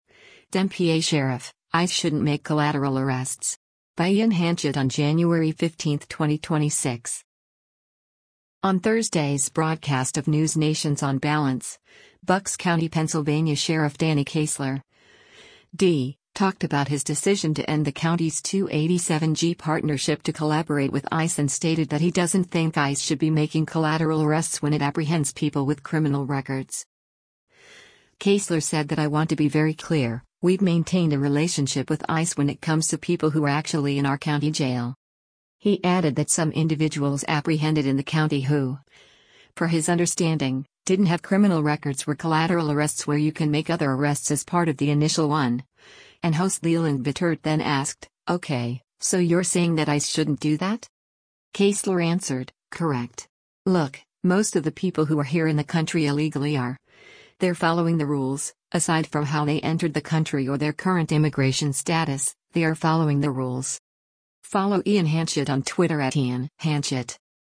On Thursday’s broadcast of NewsNation’s “On Balance,” Bucks County, PA Sheriff Danny Ceisler (D) talked about his decision to end the county’s 287(g) partnership to collaborate with ICE and stated that he doesn’t think ICE should be making collateral arrests when it apprehends people with criminal records.